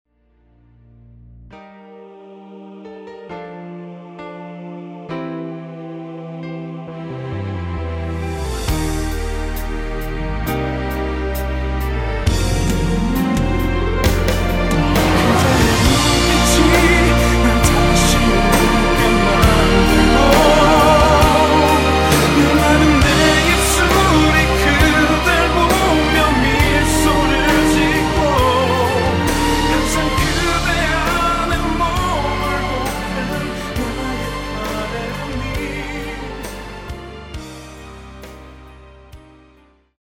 내린 MR입니다.
뒷부분 코러스 포함된 버젼 입니다 (미리듣기 참조 하세요)
Bb
중간에 음이 끈어지고 다시 나오는 이유는